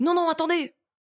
VO_ALL_Interjection_01.ogg